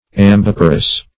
Search Result for " ambiparous" : The Collaborative International Dictionary of English v.0.48: Ambiparous \Am*bip"a*rous\, a. [L. ambo both + parere to bring forth.]
ambiparous.mp3